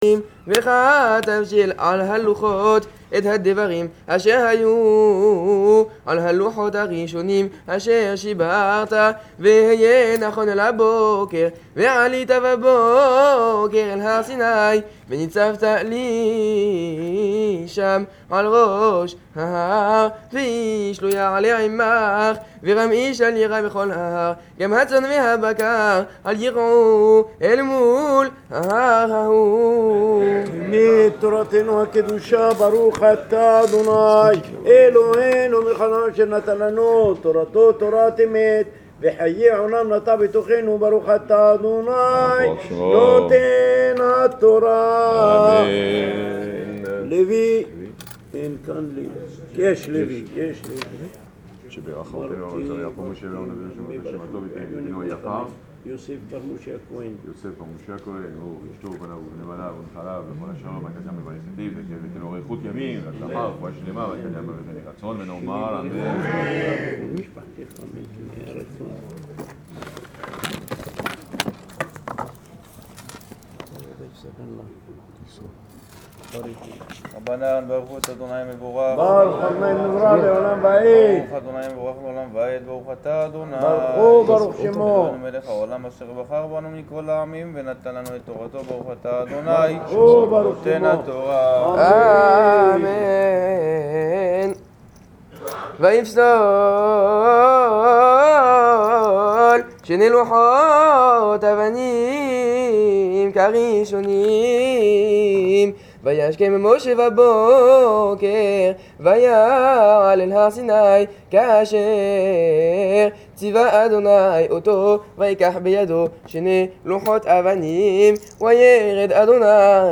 Pessah - Lecture du 5ème Jour de Pessah, à la Magnifique Synagogue "Shoham" de l’Hotel Hilton de EILAT en Israël